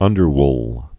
(ŭndər-wl)